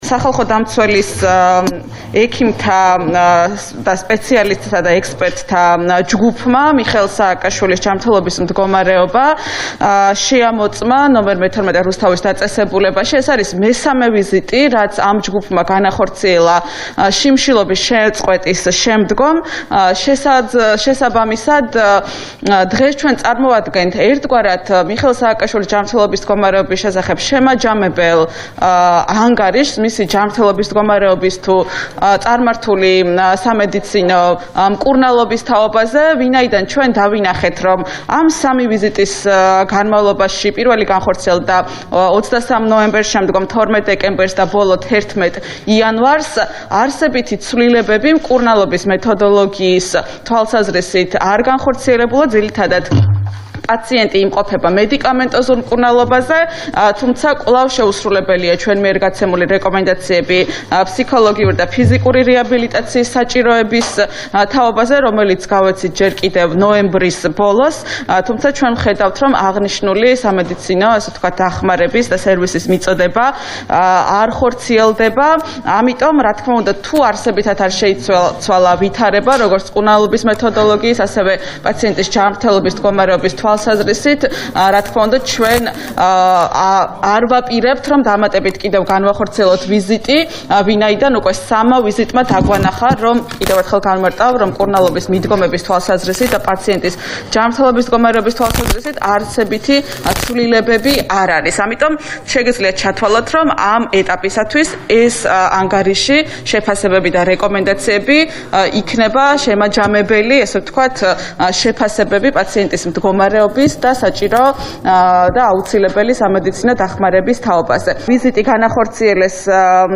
ნინო ლომჯარიას ხმა